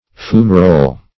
Search Result for " fumarole" : The Collaborative International Dictionary of English v.0.48: Fumarole \Fu"ma*role\, n. [It. fumaruola, fr. fumo smoke, L. fumus: cf. F. fumerolle, fumarolle.]
fumarole.mp3